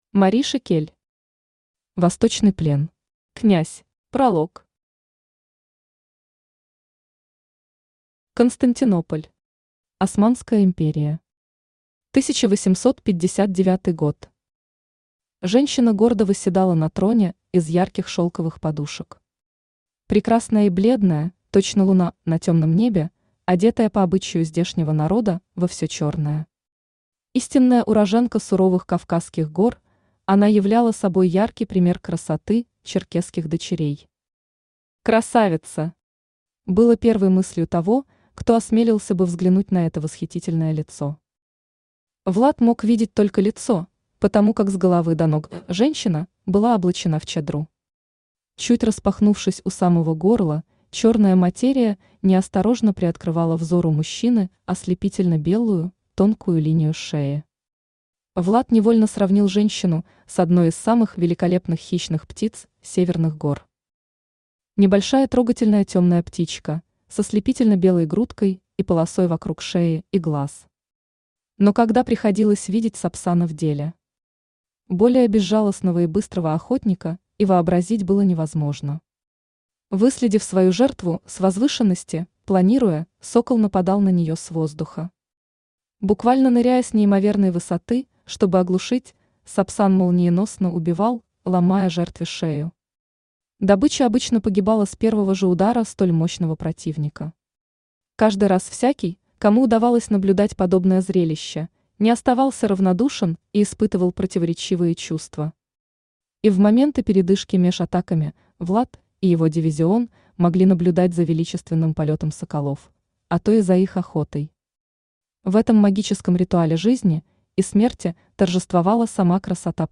Аудиокнига Восточный плен. Князь | Библиотека аудиокниг
Князь Автор Мариша Кель Читает аудиокнигу Авточтец ЛитРес.